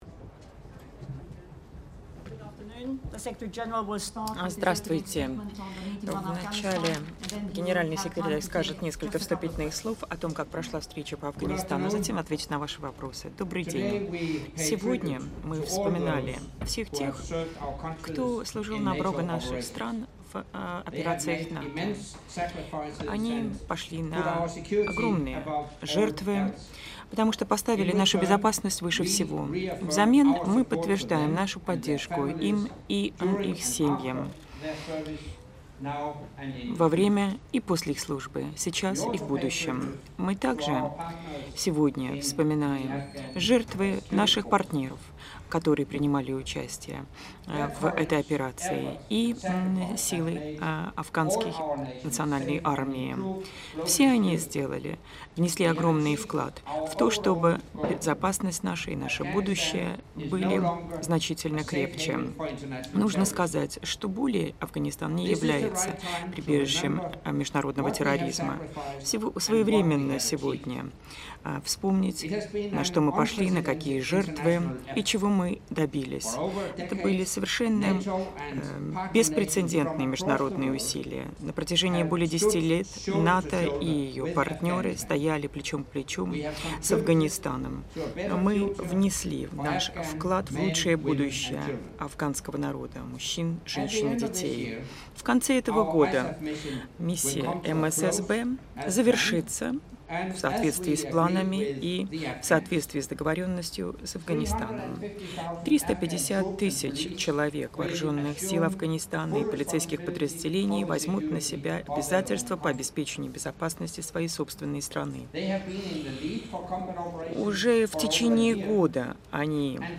Press Conference by the NATO Secretary General Anders Fogh Rasmussen following the meeting on Afghanistan at the level of Heads of State and Government - Opening remarks